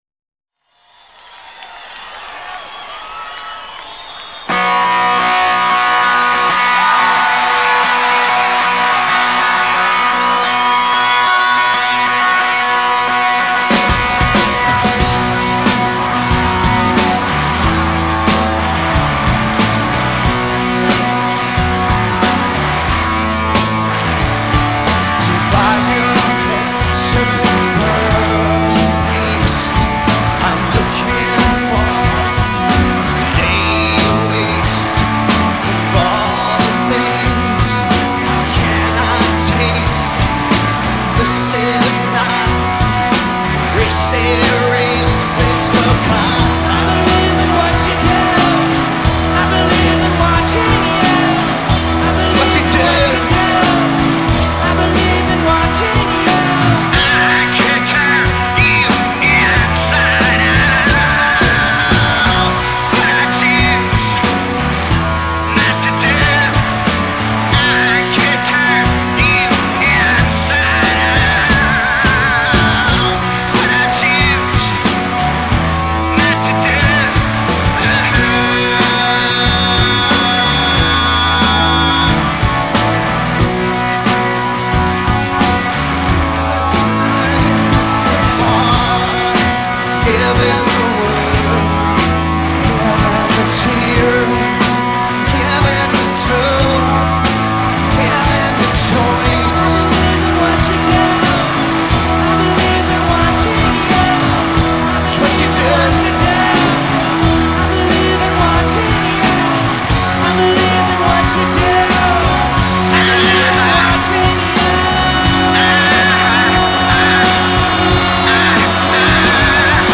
just slams the snot out of the drums